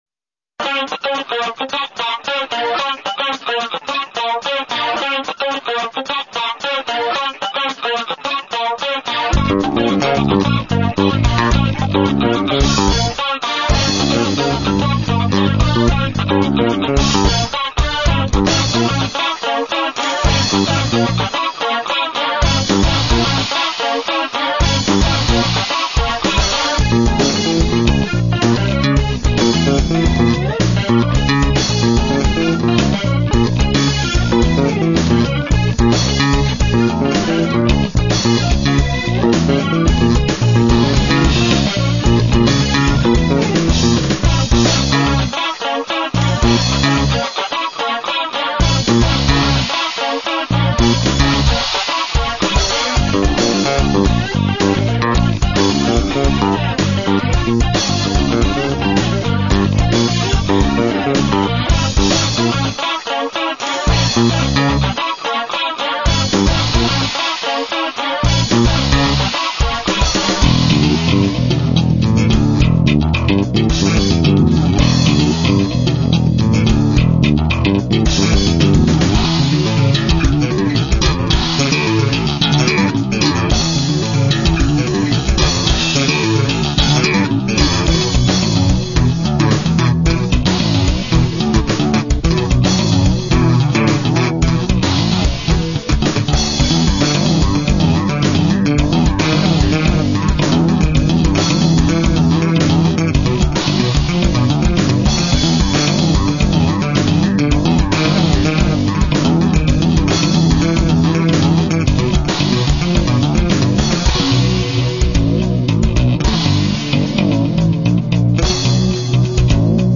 БАС-ГИТАРА